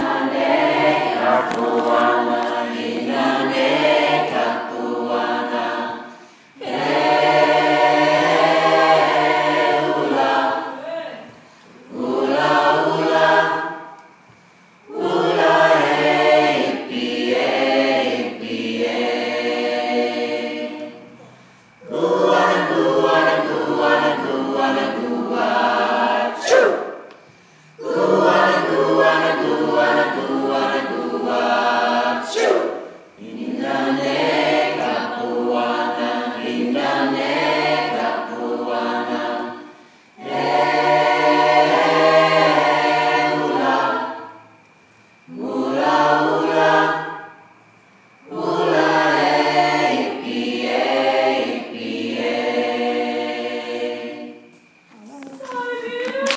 Now you can finally hear what I hear from up the front!